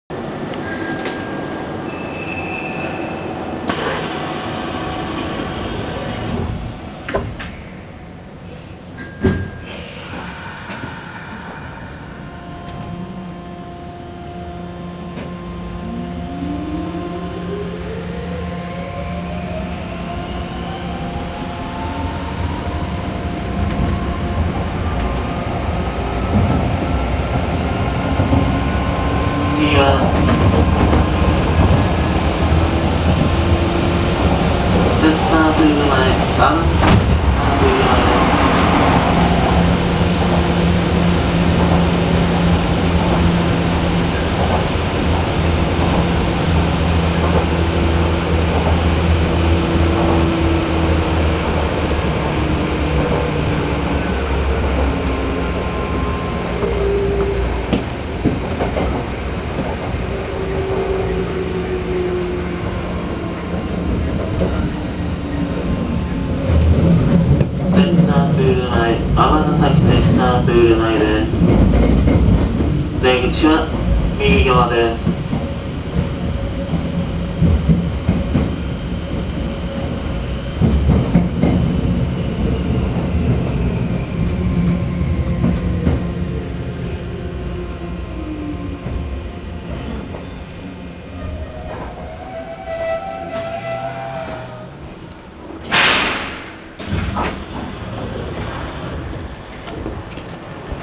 〜車両の音〜
・5331形走行音
【阪神本線】出屋敷→尼崎センタープール前（1分40秒：548KB）
チョッパ制御独特の低音がよく響くのが特徴となります。この車両もジェットカーなので加減速の性能はとんでもない事になっていますが、センタープール前駅到着前は速度を落とす（この列車は待避待ちがあった）ため、いまいちその性能が解りづらい音になってしまったかもしれません。